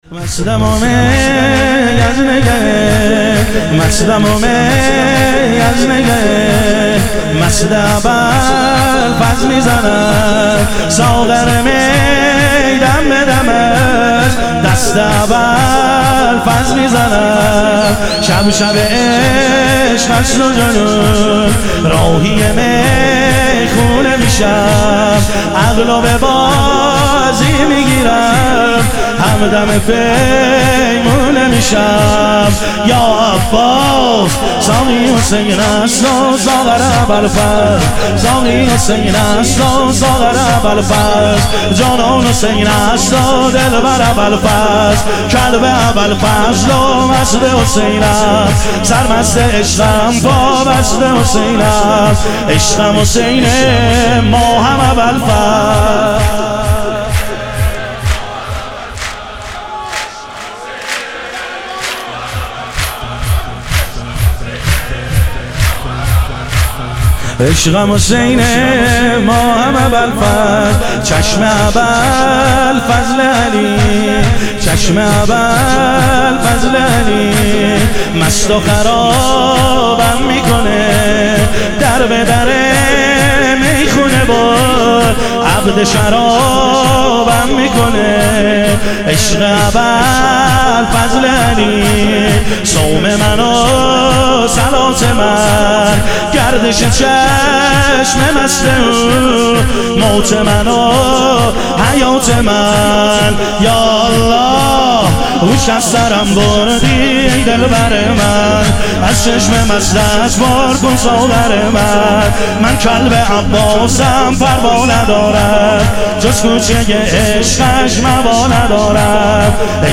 ظهور وجود مقدس امام حسین علیه السلام - شور